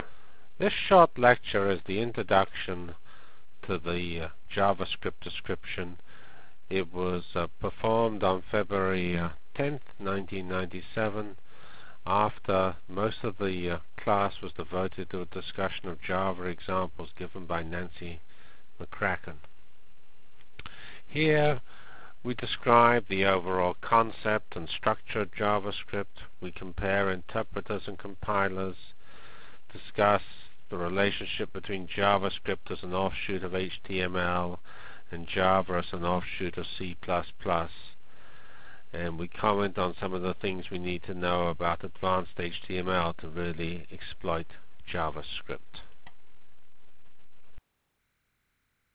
From Feb 10 Delivered Lecture for Course CPS616 -- Introduction to JavaScript CPS616 spring 1997 -- Feb 10 1997.